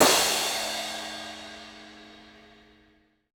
Crashes & Cymbals
Metro Crash 4.wav